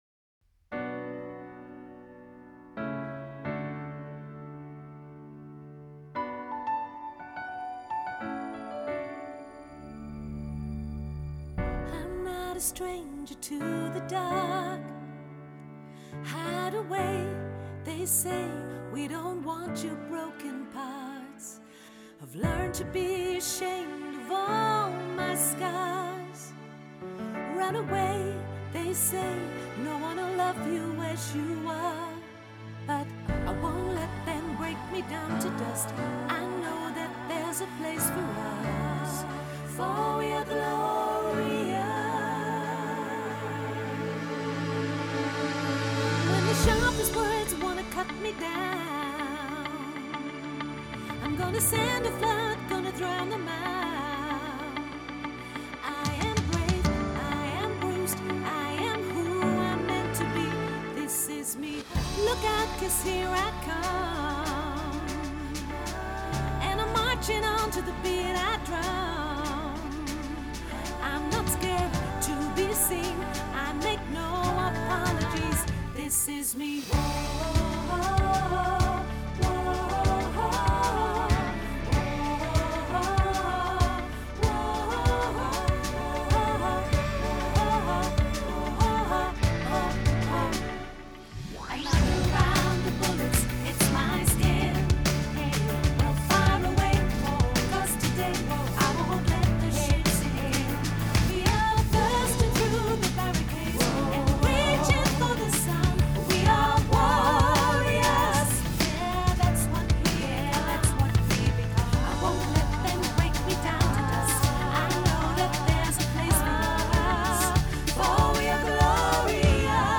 zacht